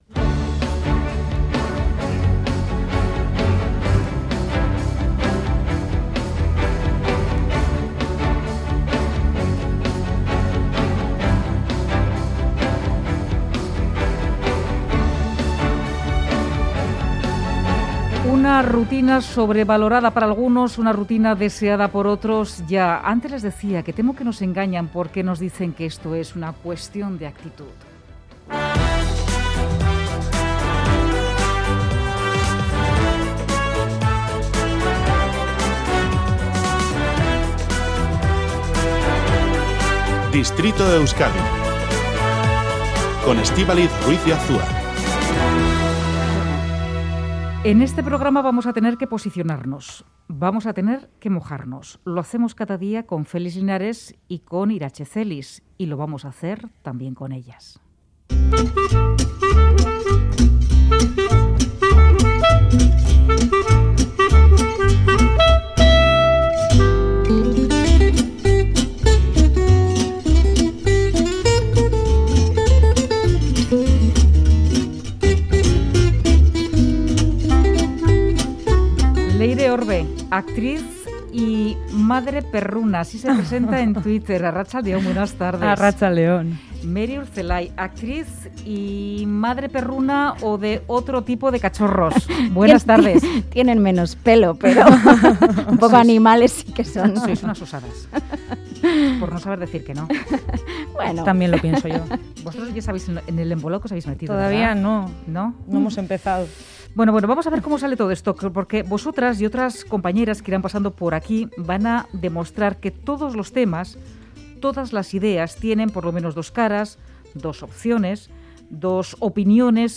Audio: Cada miércoles 'Distrito Euskadi' despliega en Radio Euskadi y Radio Vitoria la Brigada 'Y tú ¿de quién eres?' Un mismo tema, dos opiniones.